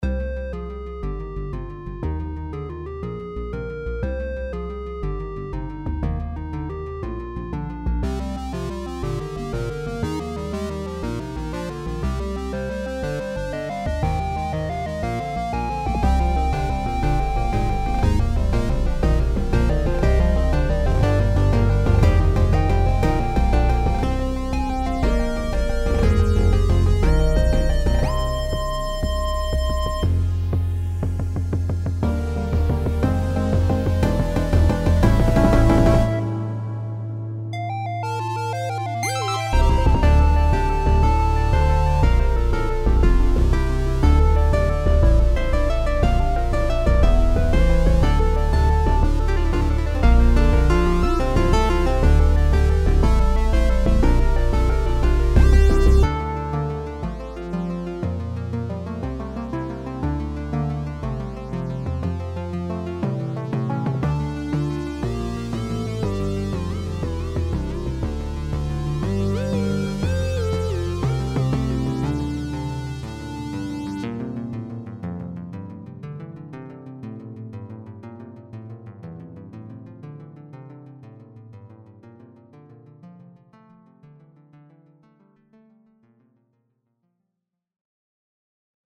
Arcade - Electronic
Think of it as an arcade game music. It's meant to go in loops.